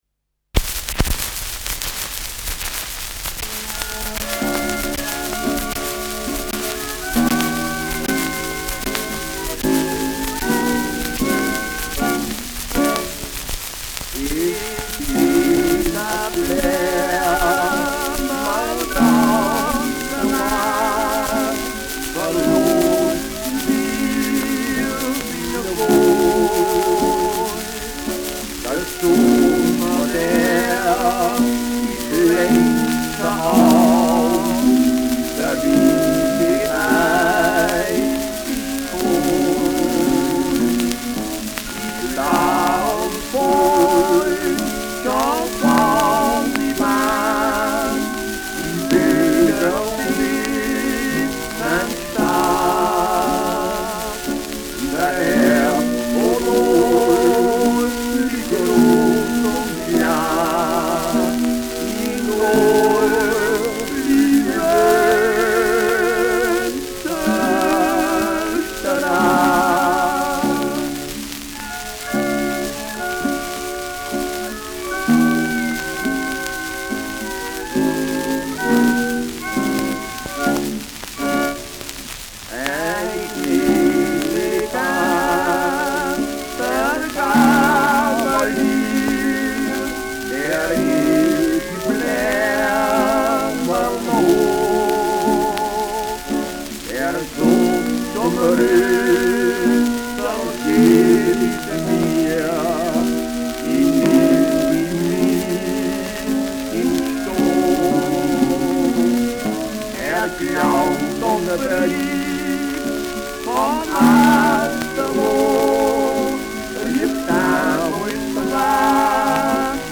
Schellackplatte
präsentes Rauschen : präsentes Knistern : leiert : abgespielt : präsentes Nadelgeräusch
Gebrüder Breier (Interpretation)
Etikett: Matador-Record : Trade Mark : s’ Waldbleamerl : C. Hirsch : Gebrüder Breier : mit Schrammelbegleitung : 54662